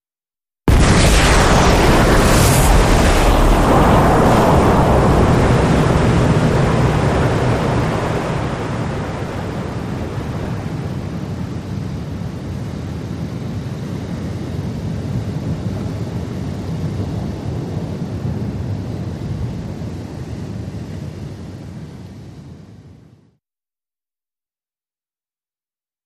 Depth Charge
Explosion, Surface Perspective Depth Charge Multiple Ver. 1